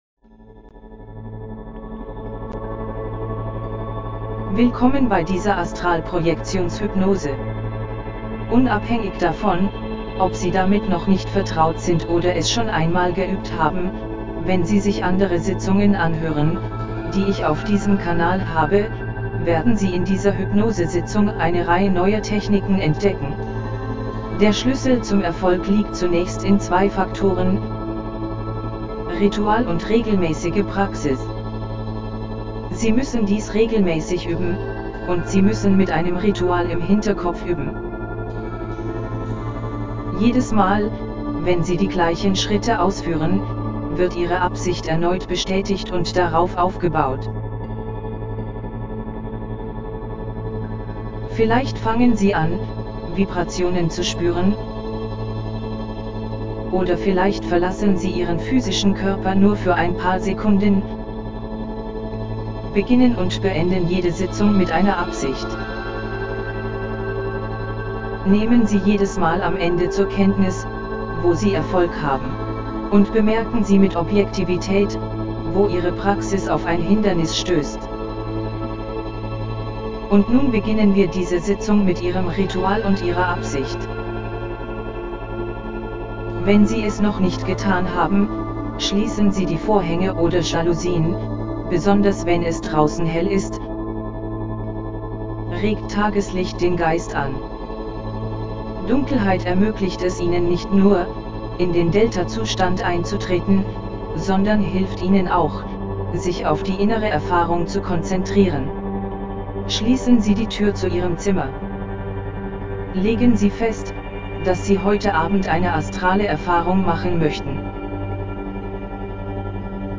Astralprojektionshypnose mit NEUEN Techniken
OBEAstralProjectionHypnosisNEWTechniquesDE.mp3